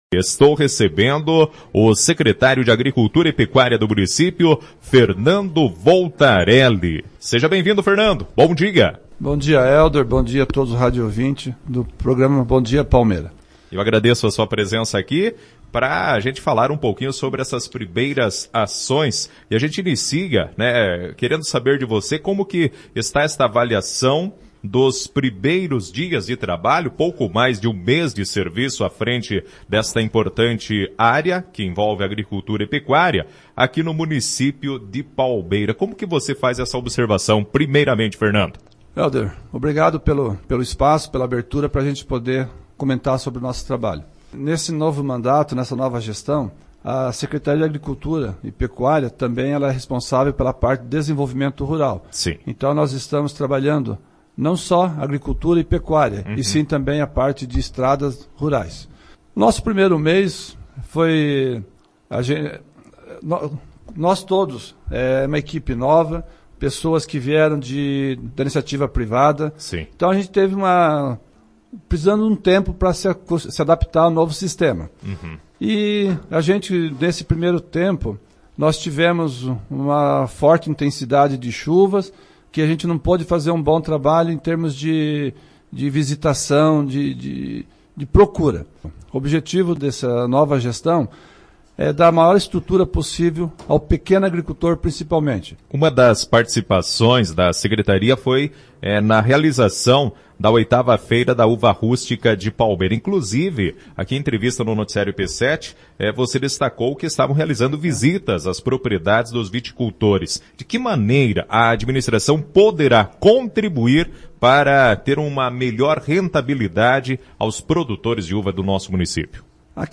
Ouça a entrevista completa com todos os assuntos abordados durante o programa: